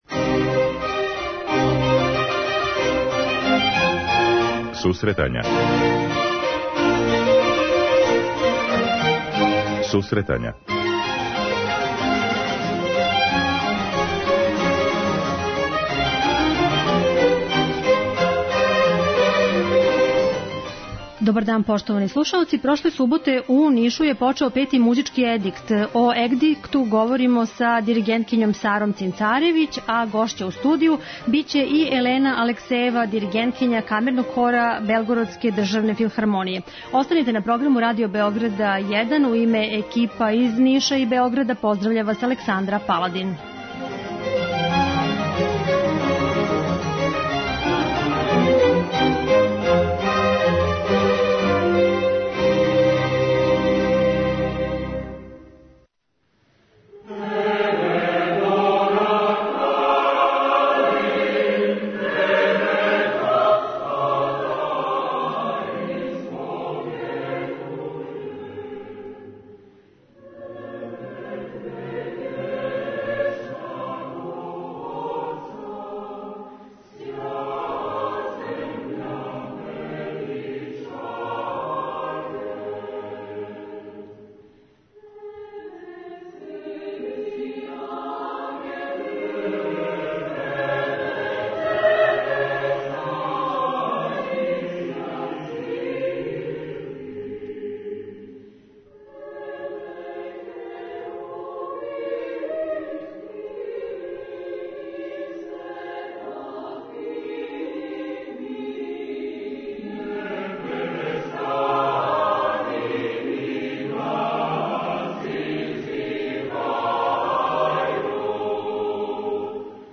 Данашња емисија реализује се из Ниша.